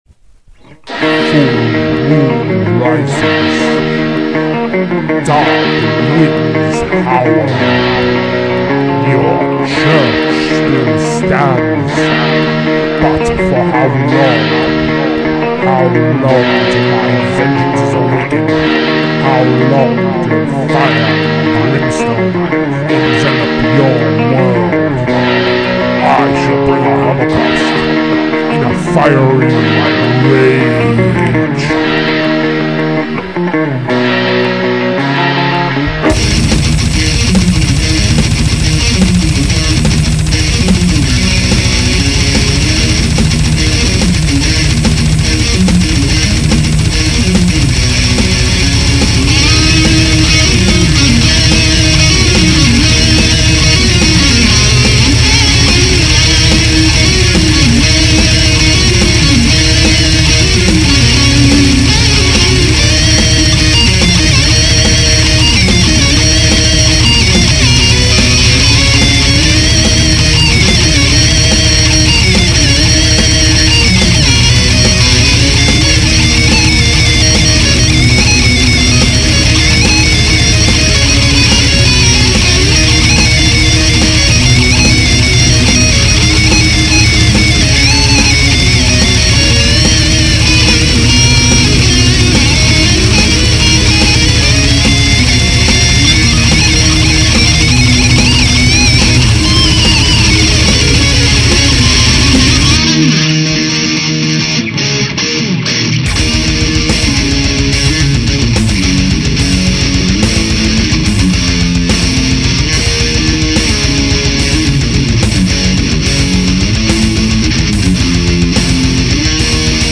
Играют "шутливый блэк-метал".